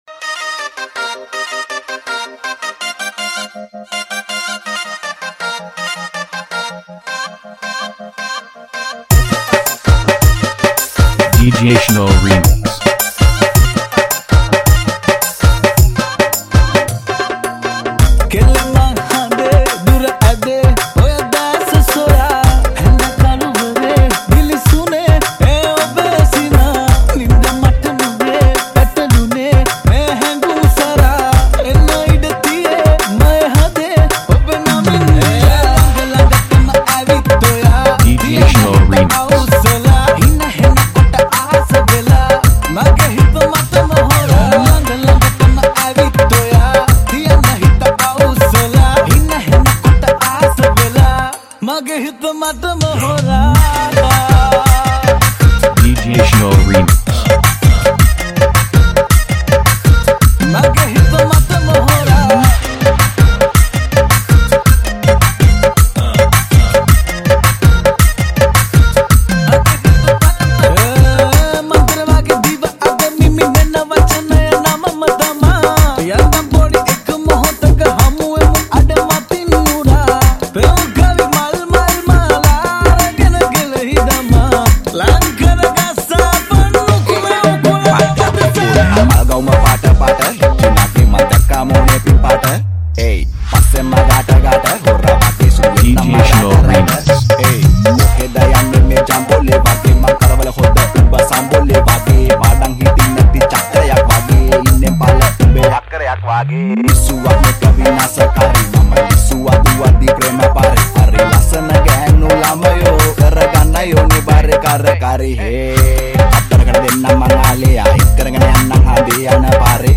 High quality Sri Lankan remix MP3 (3).
high quality remix